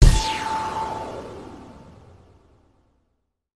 Cut-in special move (sound effects)
It is a cut-in sound effect of the special move of the game....